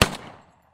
rifle_shot.wav